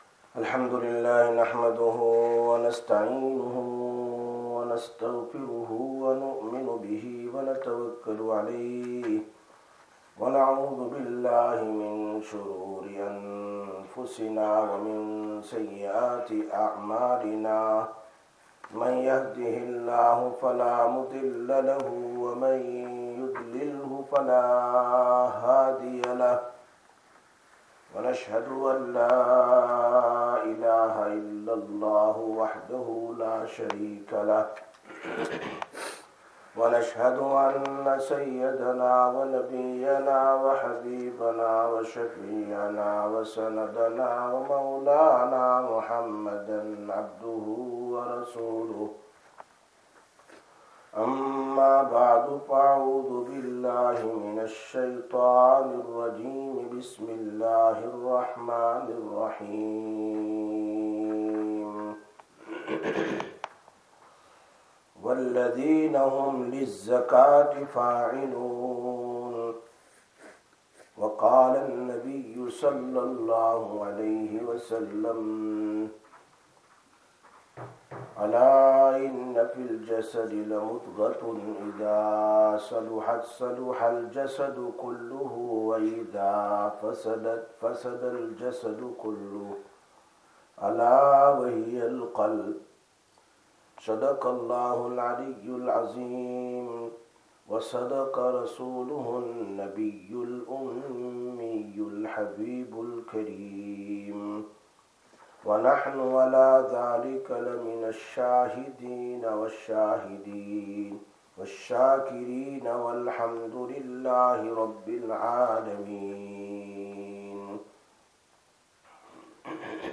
20/08/2025 Sisters Bayan, Masjid Quba